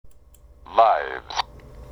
＊↑動詞のliveに３単現のsをつけたlivesとは発音が違います!
名詞のlives（リスニング用音声）